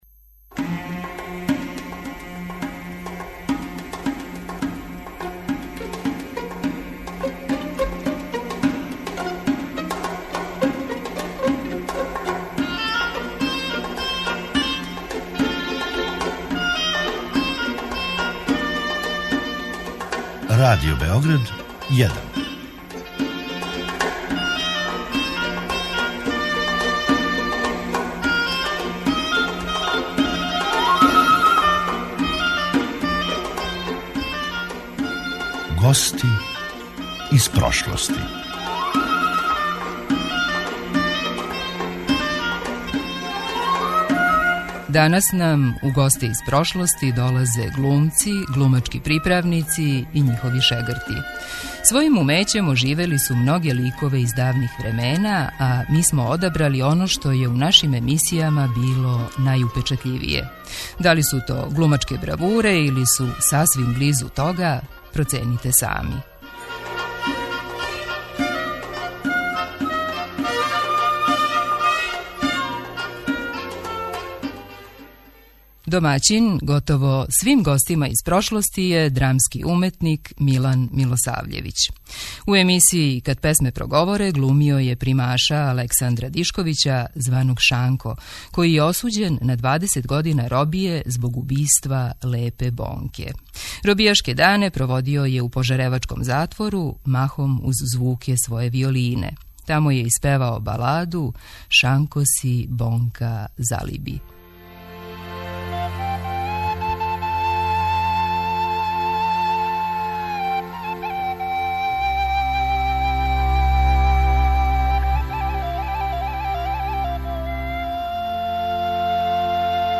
Данас нам у госте из прошлости долазе глумци, глумачки приправници и њихови шегрти. Својим умећем оживели су многе ликове из давних времена, а ми смо одабрали оно што је у нашим емисијама било најупечатљивије.